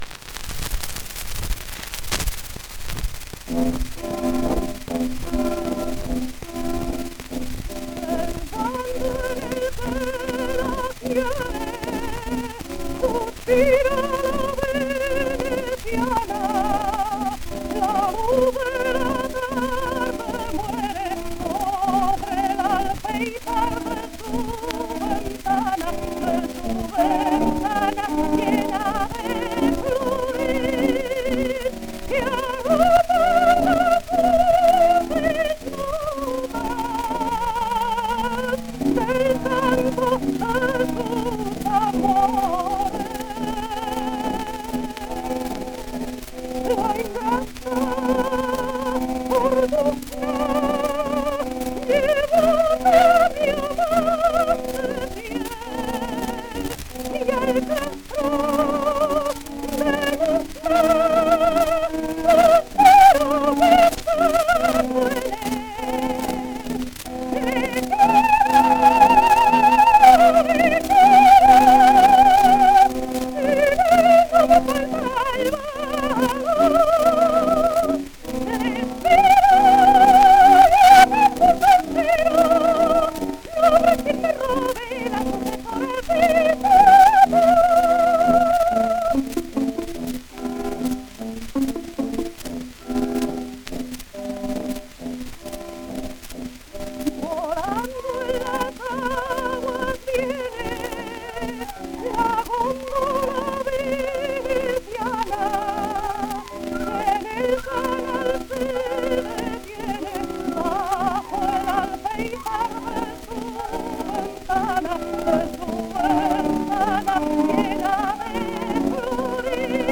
1 disco : 78 rpm